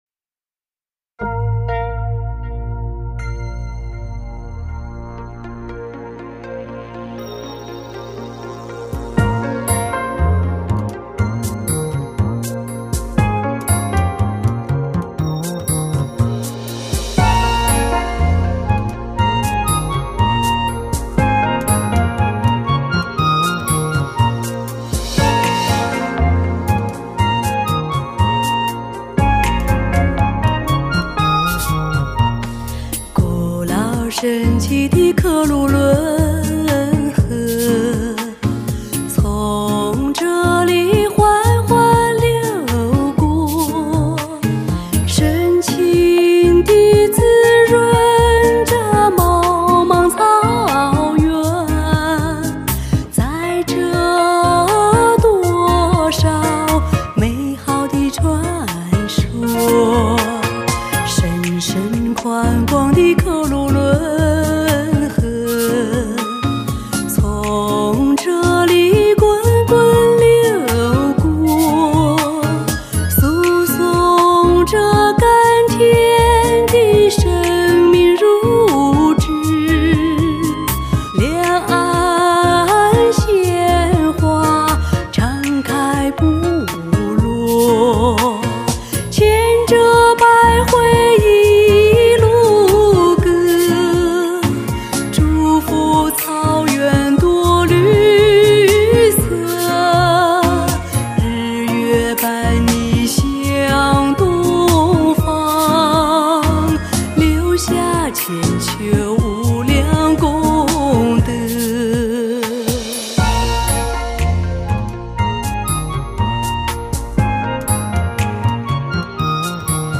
中国发烧界草原歌曲第一实力唱将！
这是中国首张蒙古民歌与现代华丽乐章完美交融的HI-FI大碟